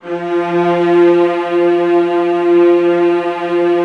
VIOLAS GN3-R.wav